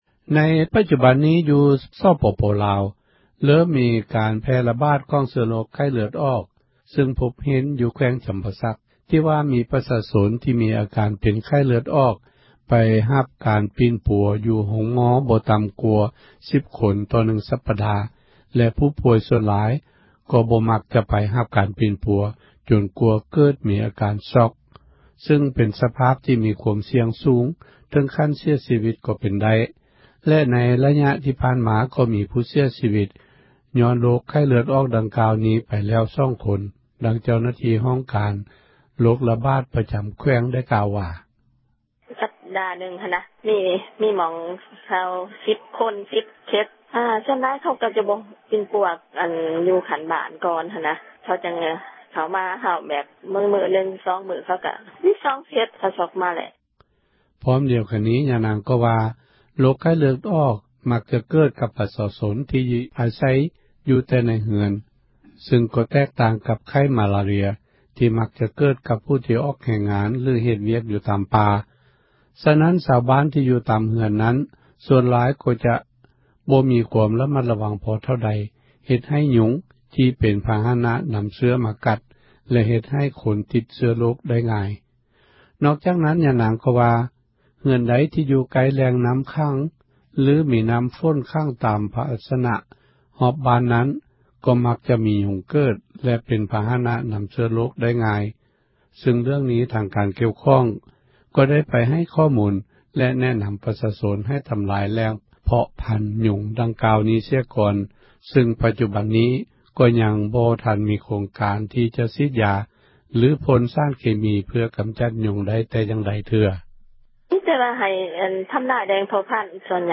ໃນປັຈຈຸບັນ ຢູ່ ສປປລາວ ເຣີ່ມມີກາຣ ແພ່ຂຍາຍ ຂອງເຊື້ອໂຣຄ ໄຂ້ເລືອດອອກ ຊຶ່ງພົບເຫັນຢູ່ ແຂວງຈຳປາສັກ ທີ່ວ່າມີ ປະຊາຊົນ ມີອາກາຣເປັນ ໄຂ້ເລືອດອອກ ໄປຮັບກາຣ ປີ່ນປົວ ຢູ່ໂຮງໝໍ ບໍ່ຕໍ່າກວ່າ 10 ຄົນ ຕໍ່ໜຶ່ງສັປດາ ແລະ ຜູ້ປ່ວຍສ່ວນຫລາຍ ກໍບໍ່ມັກຈະໄປ ຮັບກາຣປິ່ນປົວ ຈົນກວ່າເກີດມີ ອາກາຣຊ໊ອຄ ຊຶ່ງ ເປັນສະພາບທີ່ມີ ຄວາມສ່ຽງສູງ ເຖີງຂັ້ນ ເສັຍຊີວີຕ ກໍເປັນໄດ້ ແລະ ໃນຣະຍະຜ່ານມາ ກໍມີຜູ້ເສັຍ ຊີວີຕ ຍ້ອນໂຣຄ ໄຂ້ເລືອດອອກ ນີ້ແລ້ວ 2 ຄົນ. ດັ່ງເຈົ້າໜ້າທີ່ ຫ້ອງກາຣ ໂຣຄຣະບາດ ປະຈຳແຂວງ ກ່າວວ່າ: